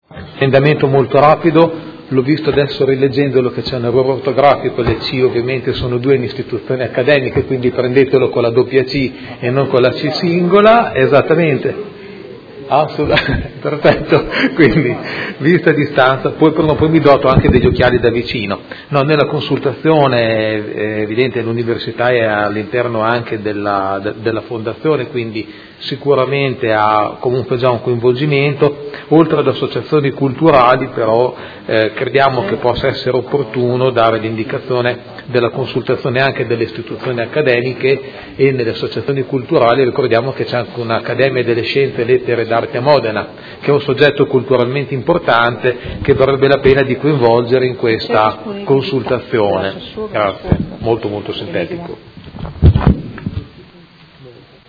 Seduta del 12/05/2016. Presenta emendamento firmato dai consiglieri Pellacani, Galli e Morandi (Forza Italia)